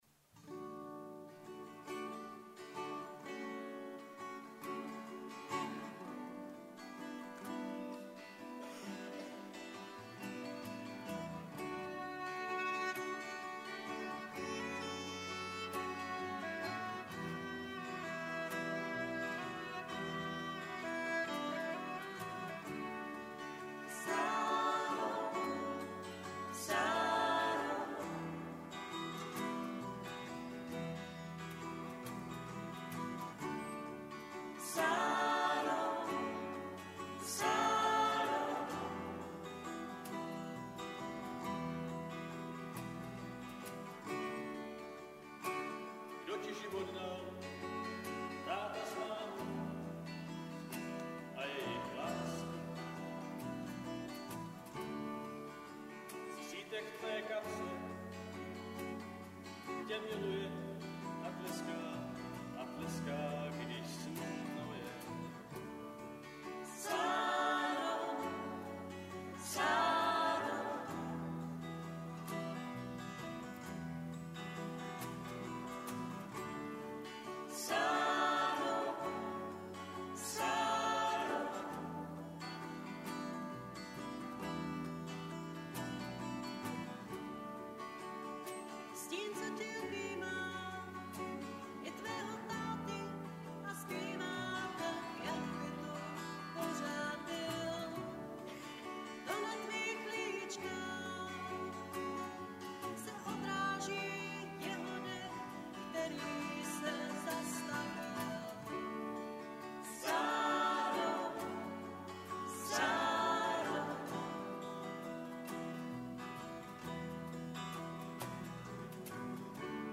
Live nahrávky: